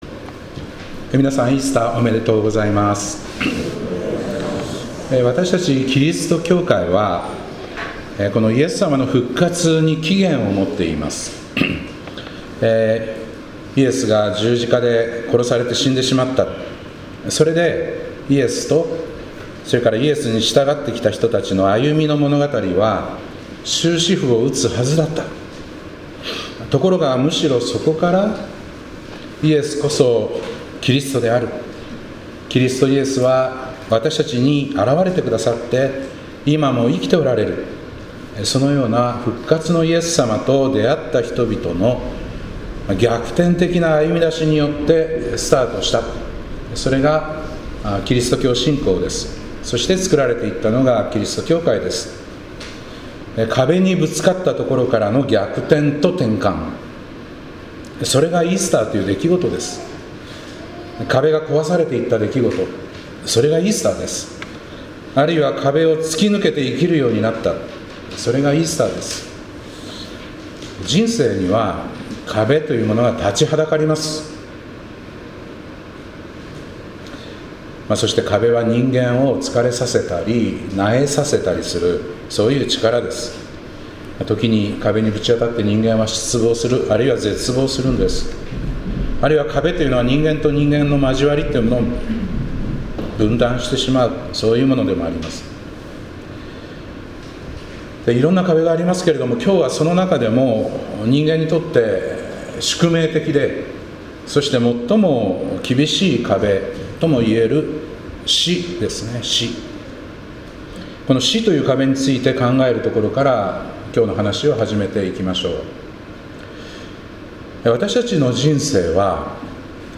2025年4月20日イースター礼拝「復活の朝、壁は壊れた」